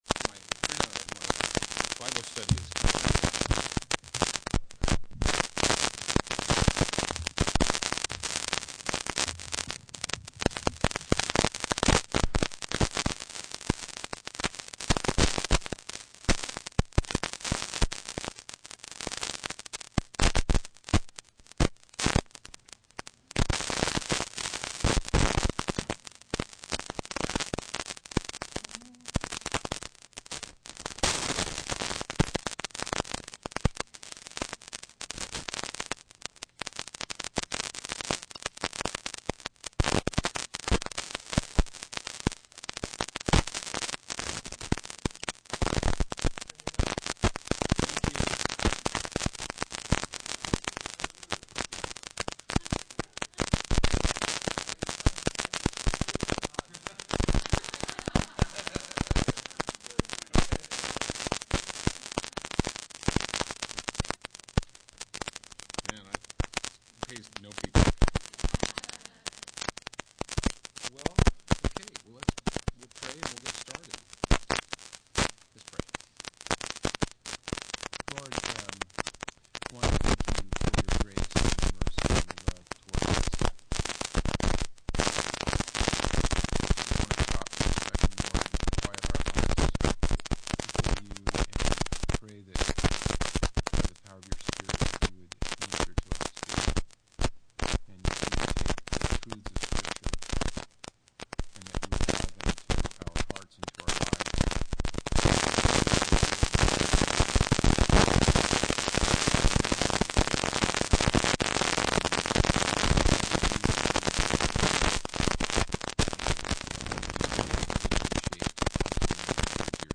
Campus: Oceanhills Church Service Type: Workshop
From The 2007 Calvary Chapel Worship Life Conference themed Give Me Jesus.